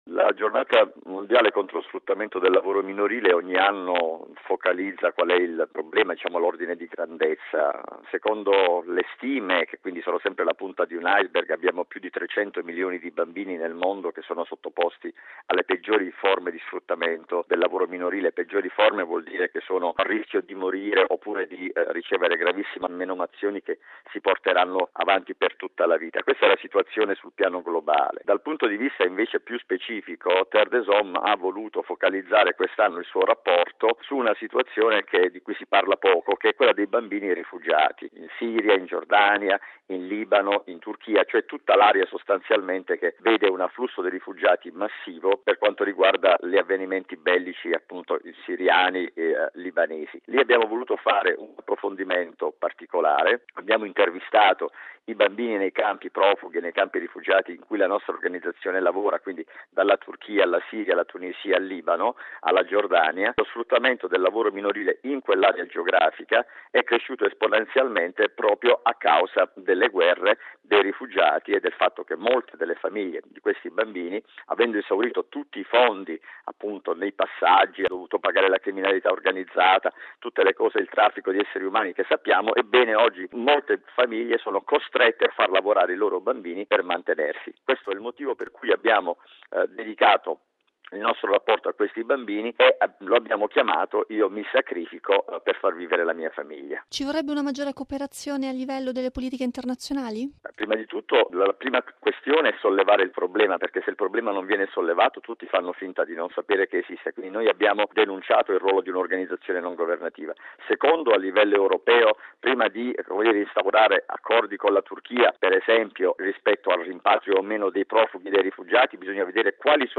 intervistato